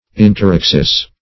Search Result for " interaxis" : The Collaborative International Dictionary of English v.0.48: Interaxis \In`ter*ax"is\, n.; pl. Interaxes .